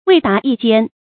未达一间 wèi dá yī jiān
未达一间发音